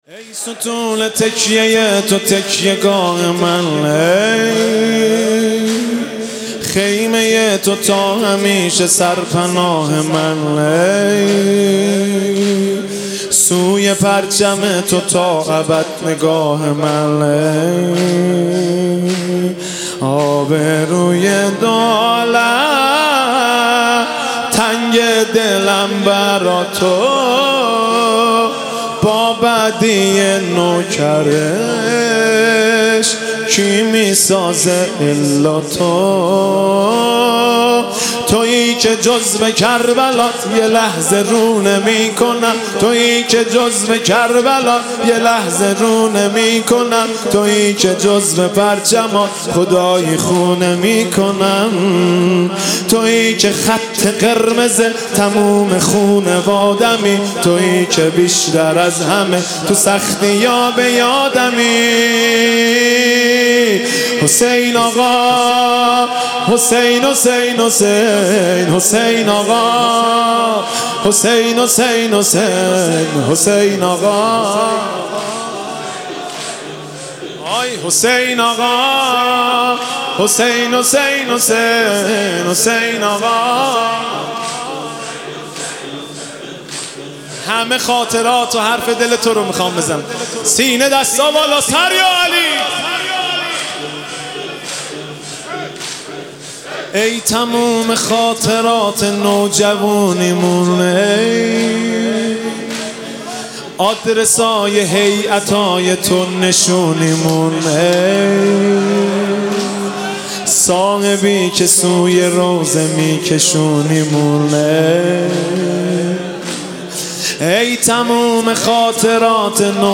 گلچین فایل صوتی سخنرانی و مداحی شب چهارم محرم، اینجا قابل دریافت است.
مهدی رسولی - زمینه